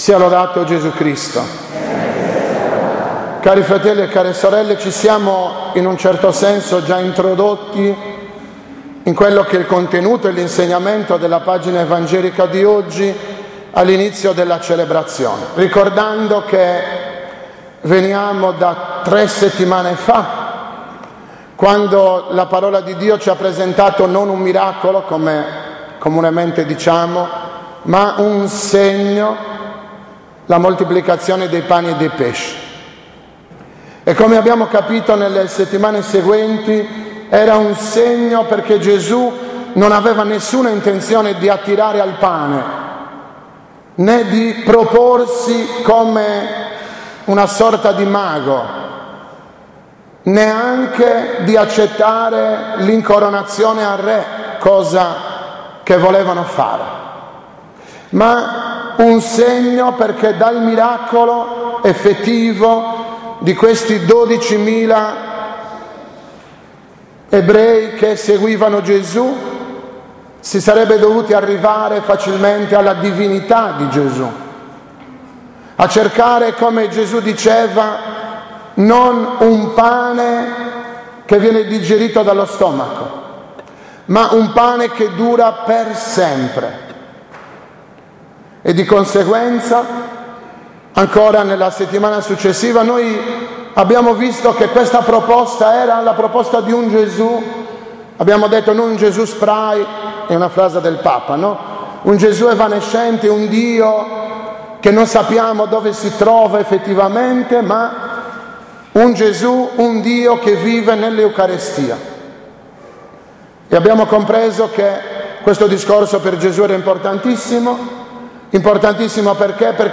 16.08.2015- OMELIA DELLA XX DOMENICA DEL TEMPO ORDINARIO